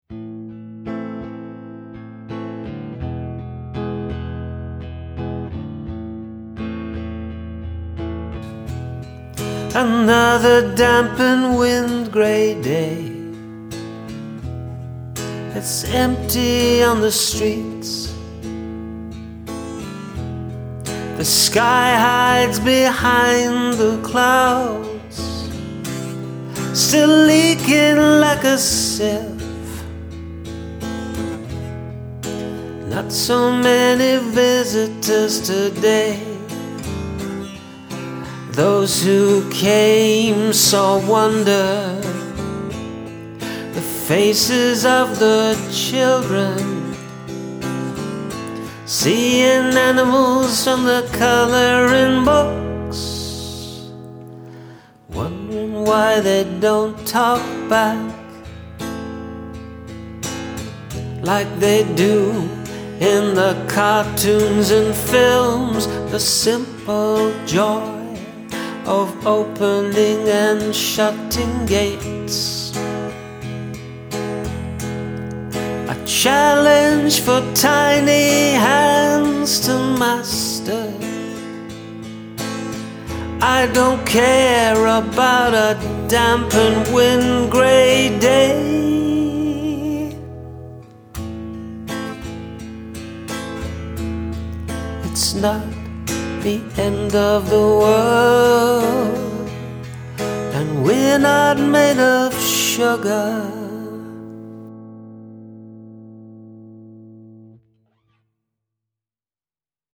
This is moody and deep.